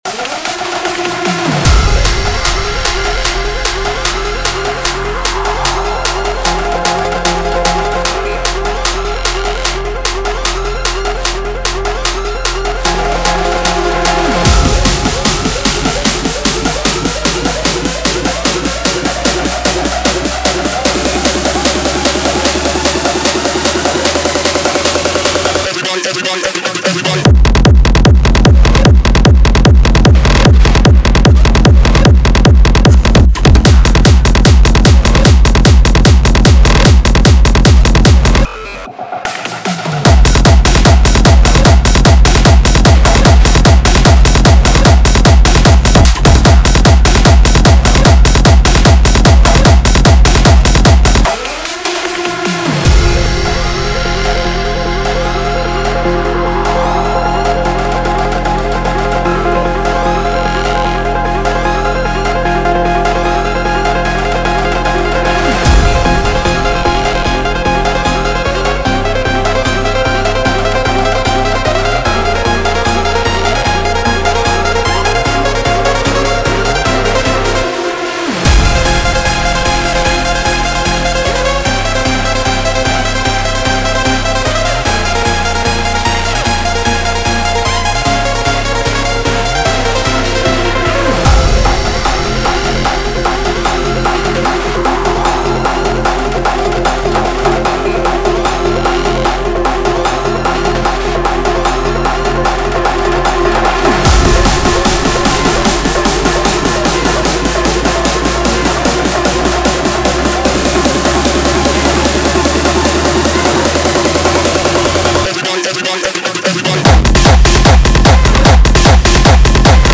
Type: Serum Samples